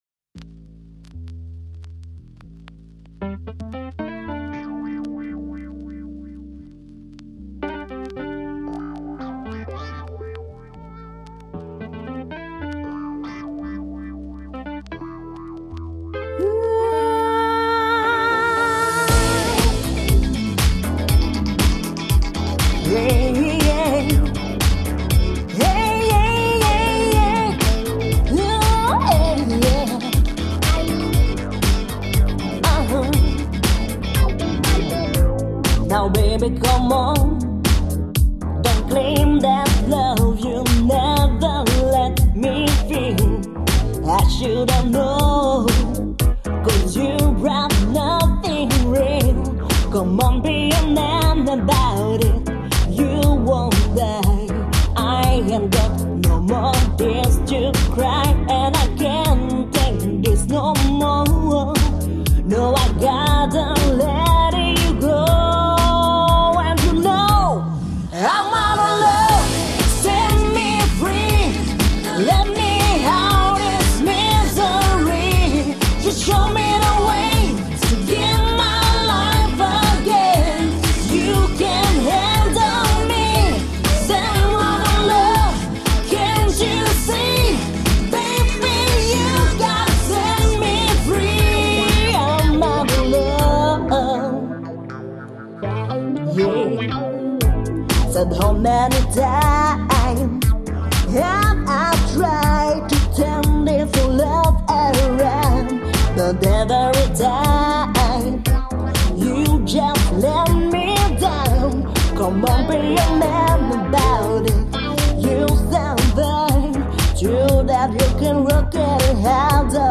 Seule (Avec bandes orchestrales).